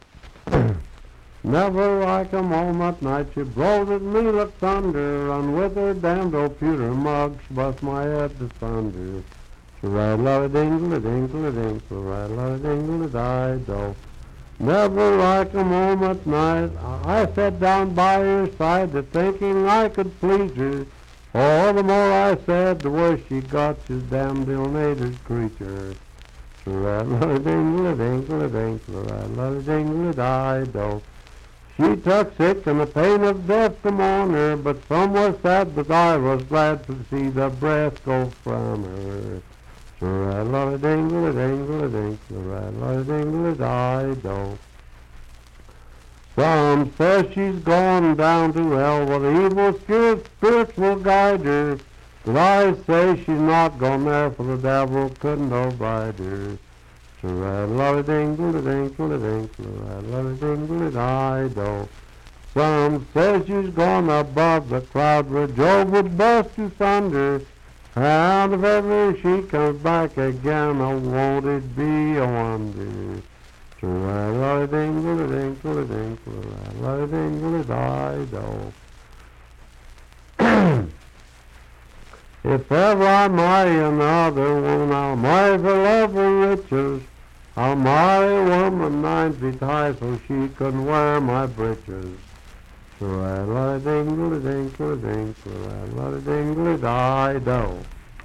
Unaccompanied vocal music
Voice (sung)
Marion County (W. Va.), Fairview (Marion County, W. Va.)